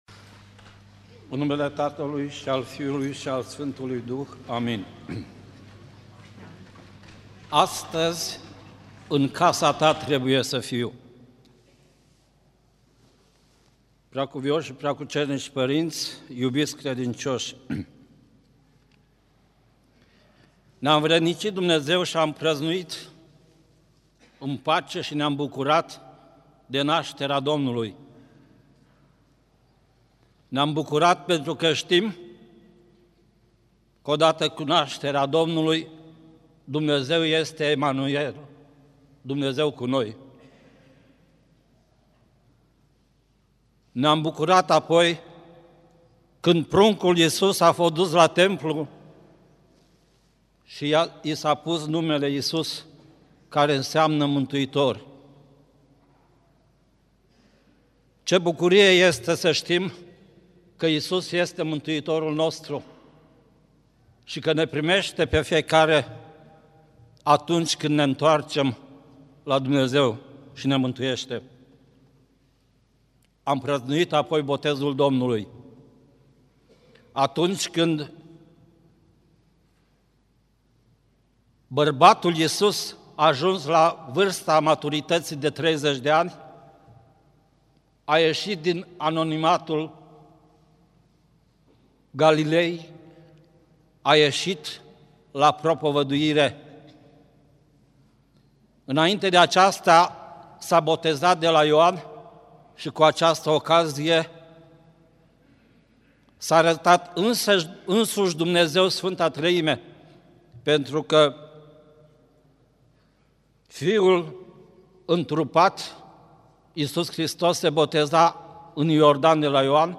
Predică la Duminica a 32-a după Rusalii
Cuvinte de învățătură Predică la Duminica a 32-a după Rusalii